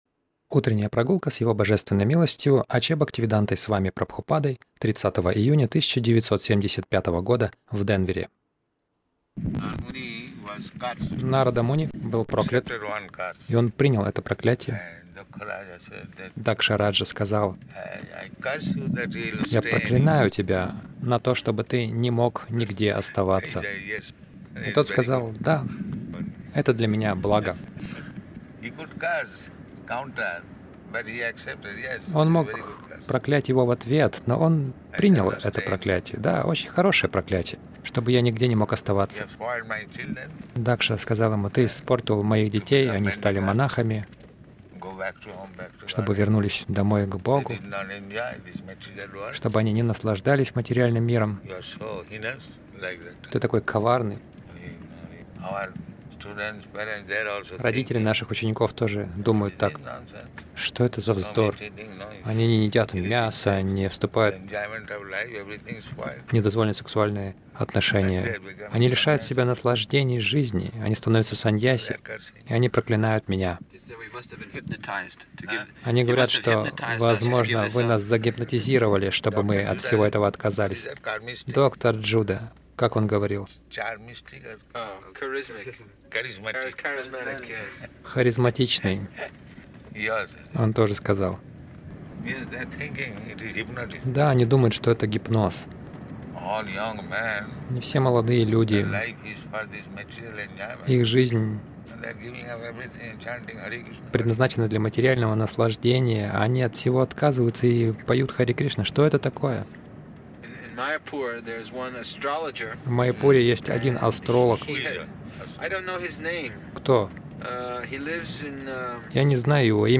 Милость Прабхупады Аудиолекции и книги 30.06.1975 Утренние Прогулки | Денвер Утренние прогулки — О деятельности Бон Махараджа Загрузка...